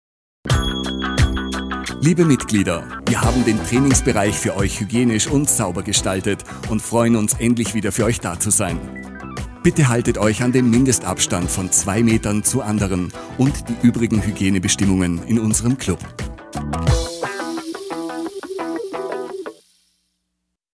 Beispielspot zum Vorhören:
1.-HYGIENE-DURCHSAGE-2-Meter.mp3